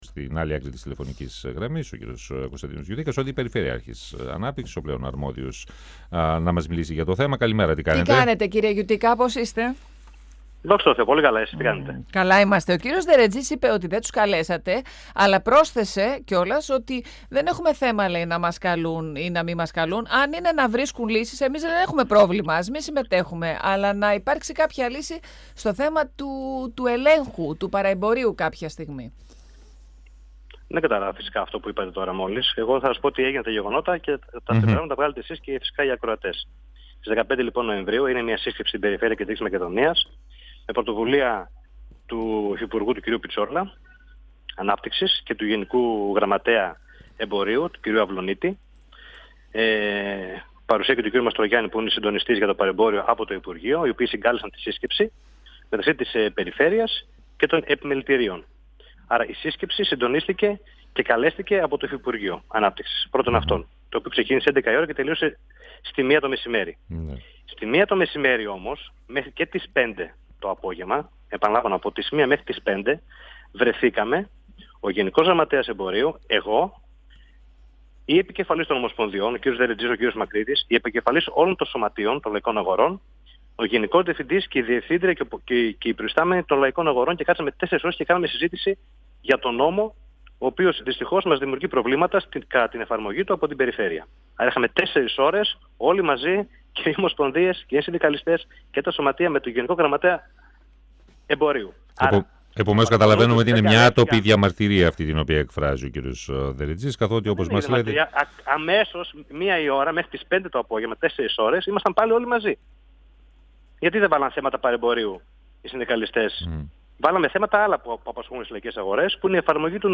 Ο αντιπεριφερειάρχης Ανάπτυξης και Περιβάλλοντος Κεντρικής Μακεδονίας, Κωνσταντίνος Γιουτίκας, στον 102FM του Ρ.Σ.Μ. της ΕΡΤ3